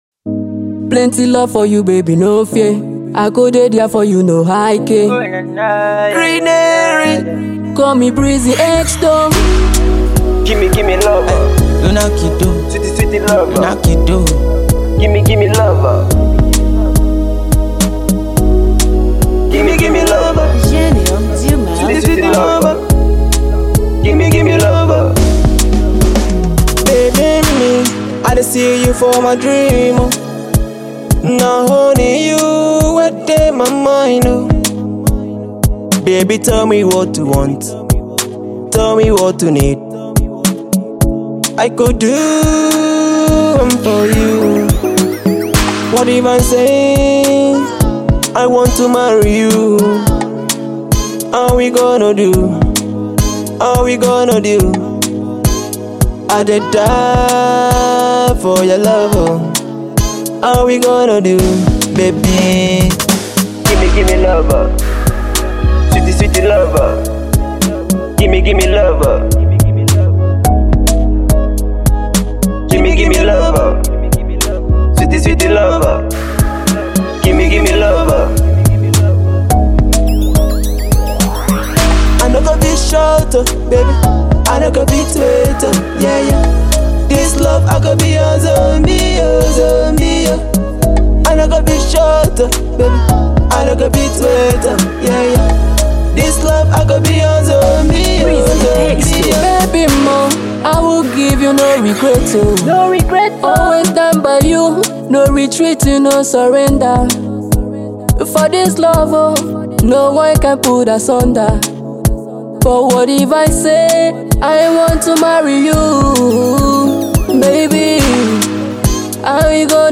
New School pop